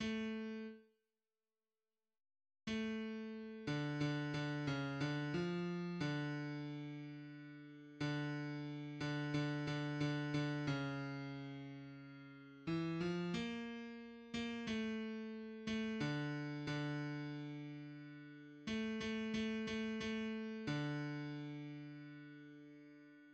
{\clef bass \tempo 4=90 \key c \major \set Score.currentBarNumber = #6 \bar "" a4 r2 r4 a4. d8 d d cis d f4 d2. d4. d8 d d d d cis2. e8f bes4. bes8 a4. a8 d4 d2. a8 a a a a4 d1 }\addlyrics {\set fontSize = #-2 - VIL- NE, SHTOT FUN GAYST UN TMI- MES, VIL- NE, YI- DISH- LEKH FAR- TRAKHT } \midi{}